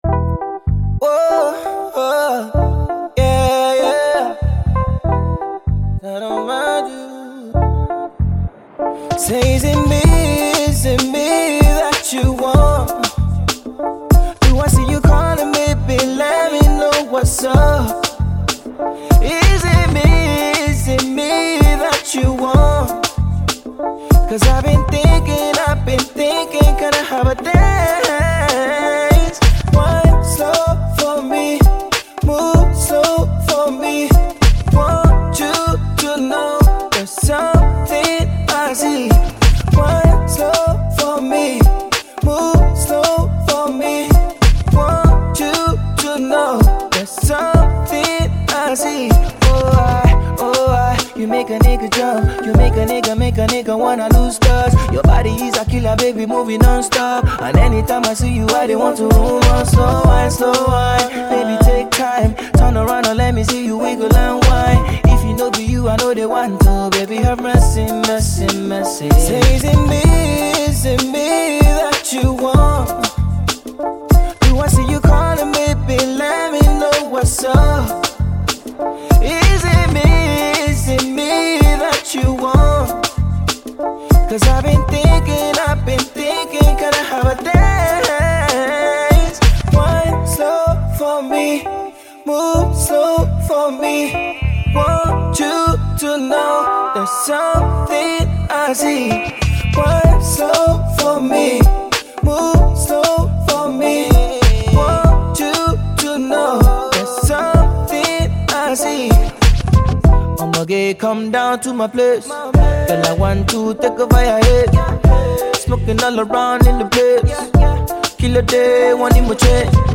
RnB
the beat is mid-tempo.